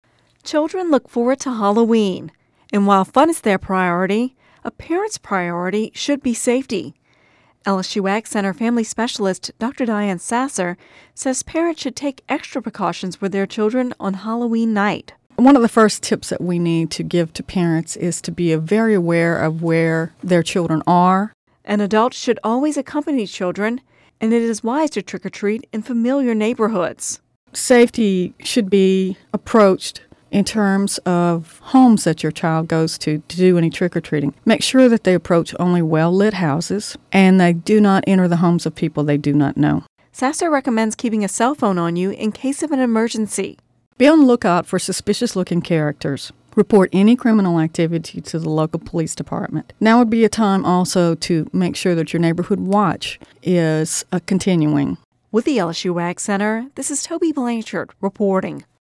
(Radio News 10/18/10) Children look forward to Halloween, and while fun is their priority, parents’ priority should be safety.